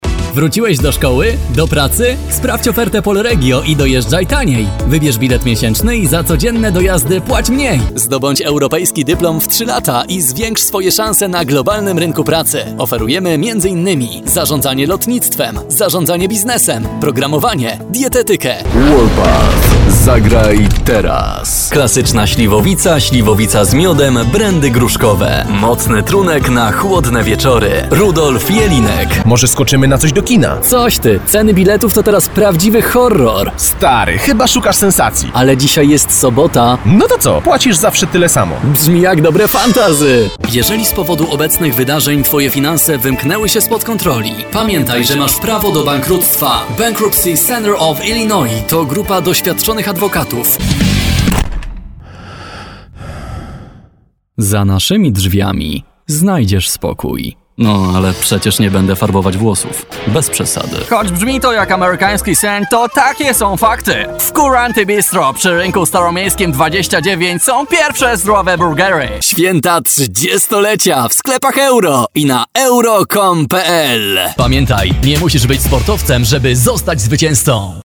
Male 20-30 lat
Young and pleasant voiceover voice.
Spot reklamowy